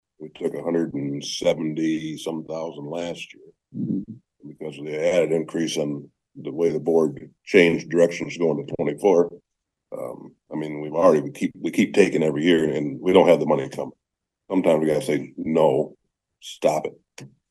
Commissioner Jon Houtz felt they should stop dipping into the fund balance.